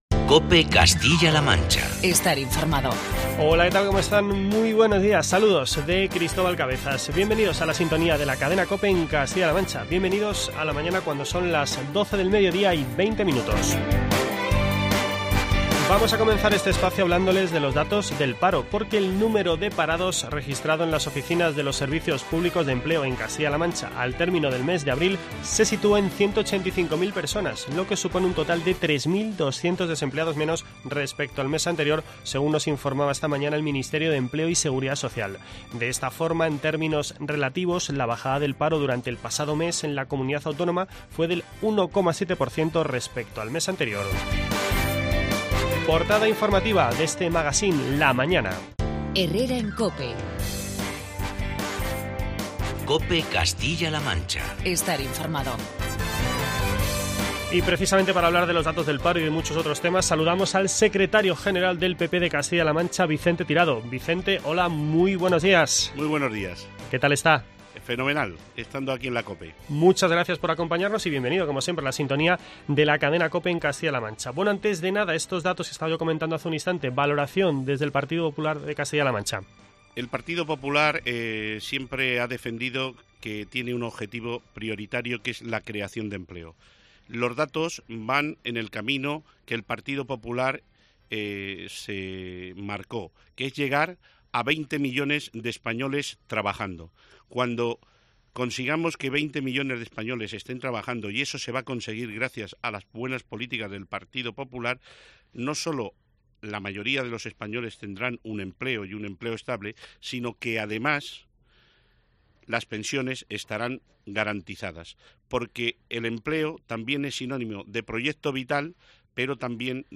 Entrevista con Vicente Tirado, secretario general del PP de Castilla-La Mancha